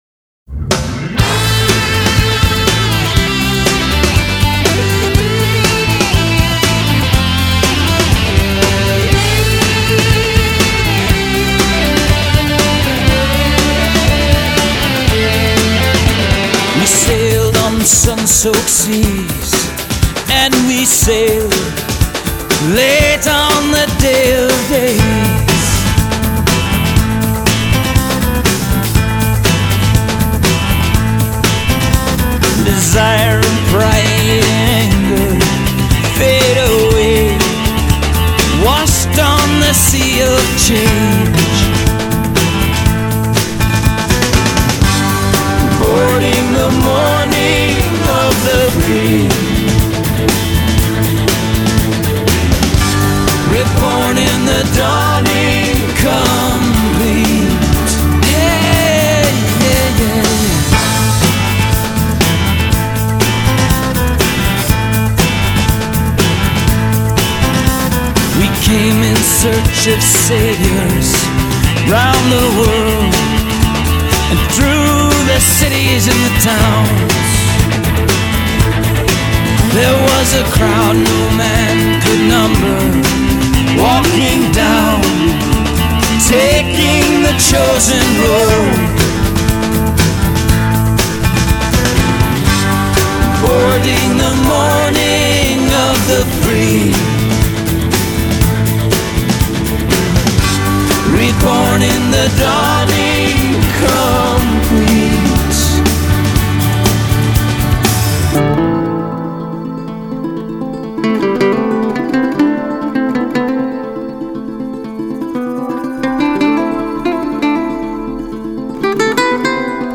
Style: Folk-Rock